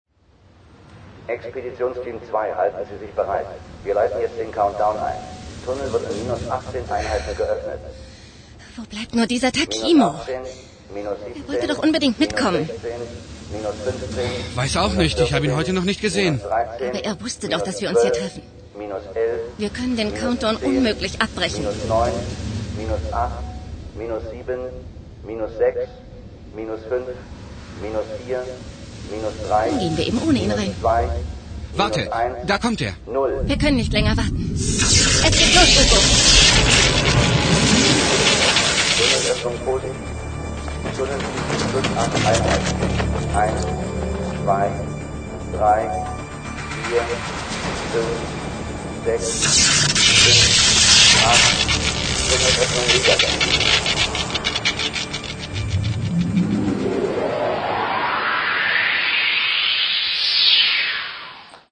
Liebevoll abgemischt entfaltet sich vor dem Zuhörer ein Klangteppich, der die Grenzen des Mediums CD voll auslotet und einiges abverlangt.
Hörprobe (mit freundlicher Genehmigung von Polaris Hörspiele, Berlin)